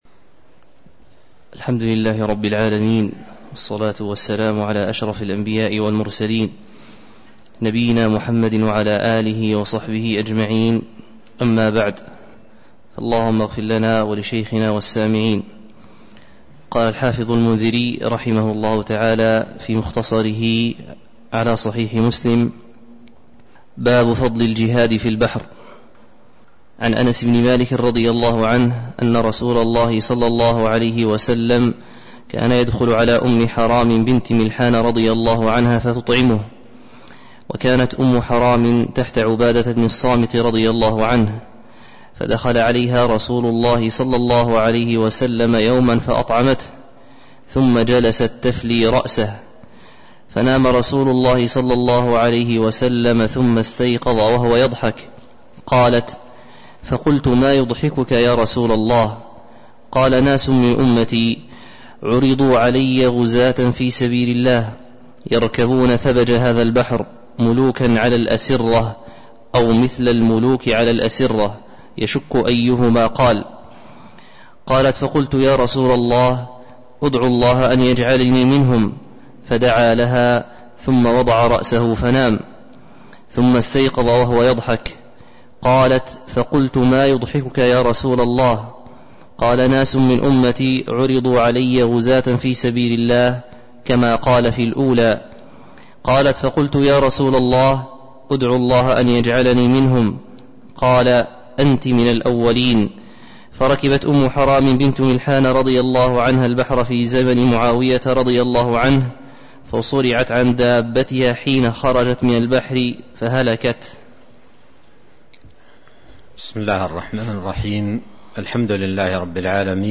تاريخ النشر ٢٢ ربيع الأول ١٤٤٣ هـ المكان: المسجد النبوي الشيخ